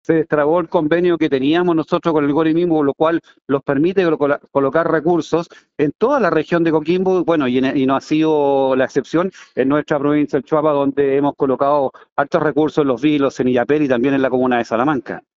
Juan Barraza – CORE Choapa